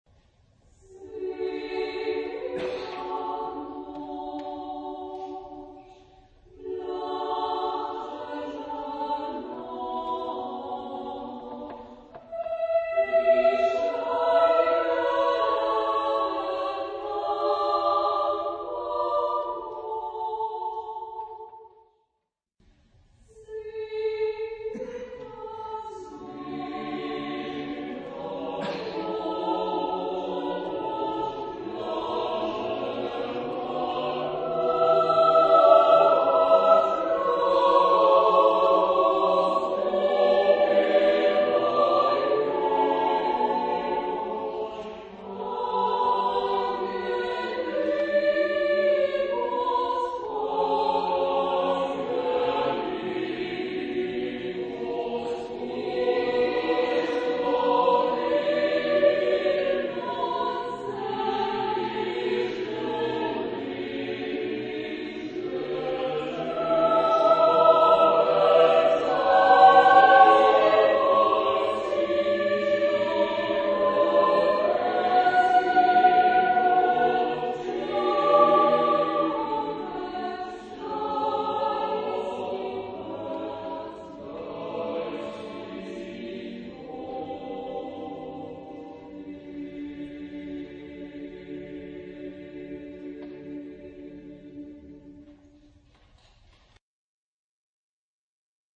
SATB (div.) (4 voices mixed) ; Full score.
Sacred. Christmas carol.